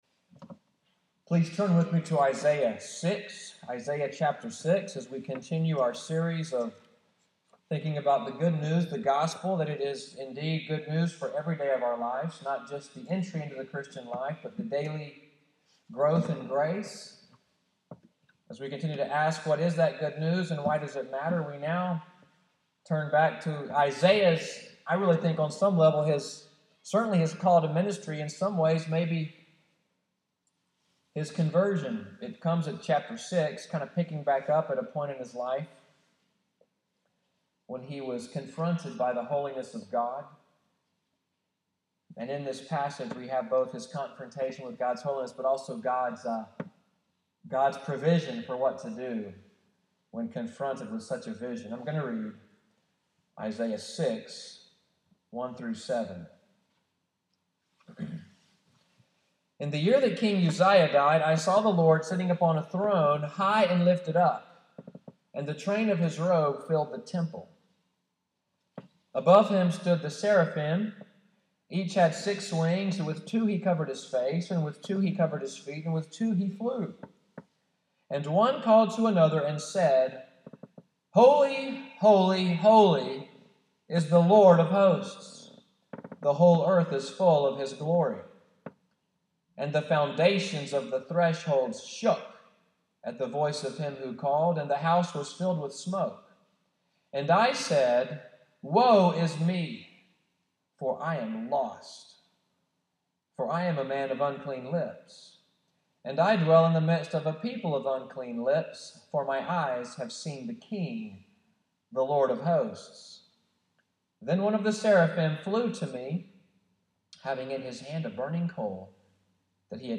Sermon text: Selections from Genesis 1-3.